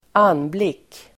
Uttal: [²'an:blik:]